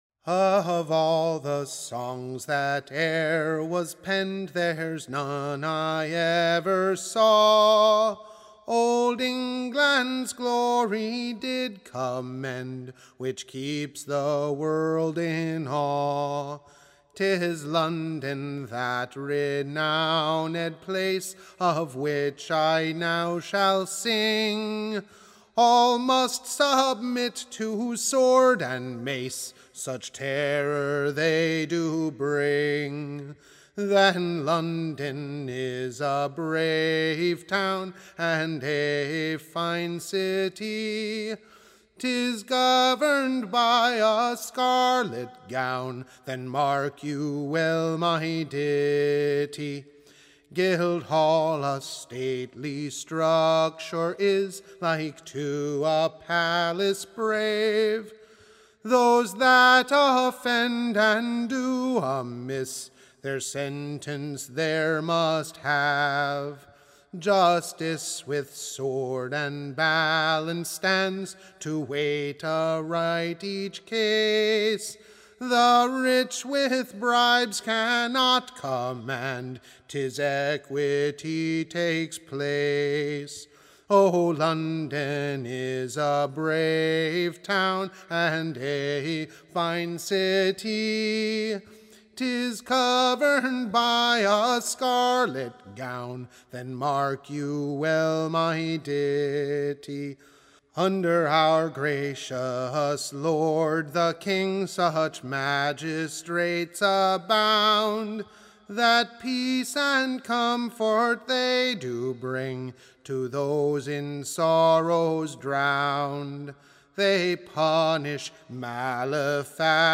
Recording Information Ballad Title LONDONS PRAISE, / OR, The Glory of the CITY.